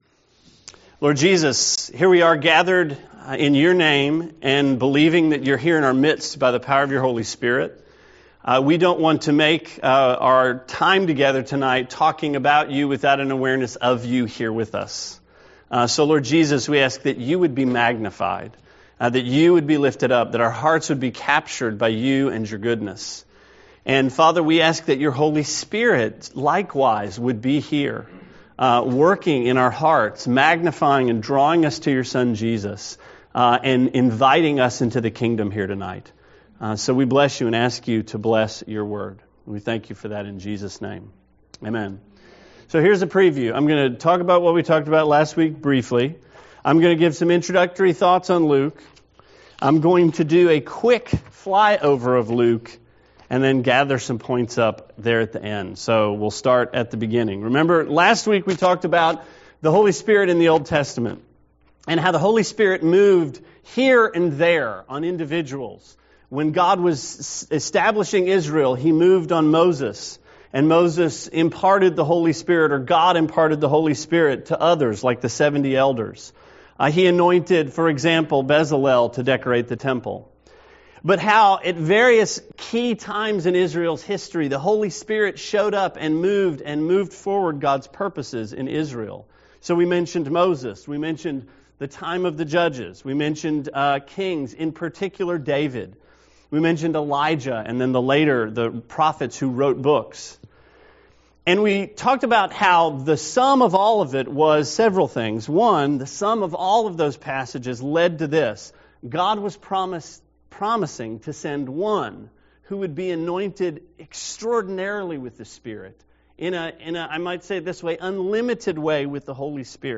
Sermon 2/5: Acts: Flyover of Luke – Trinity Christian Fellowship